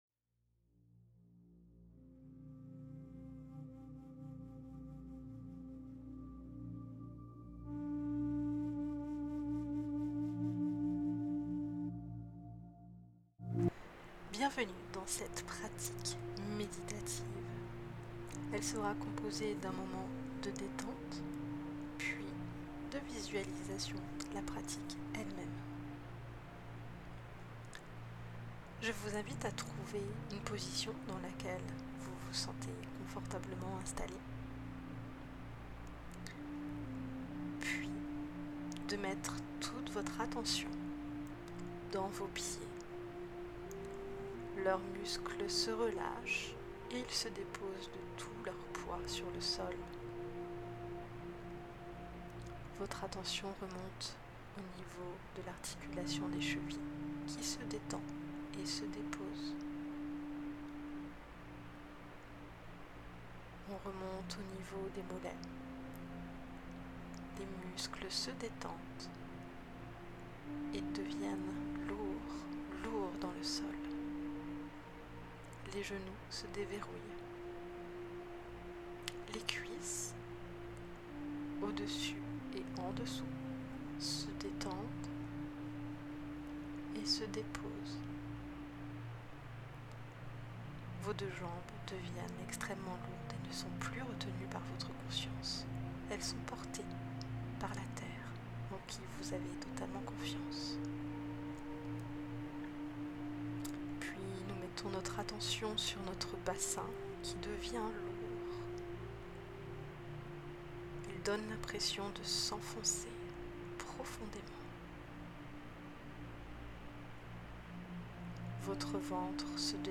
Meditation-champ-denergie.mp3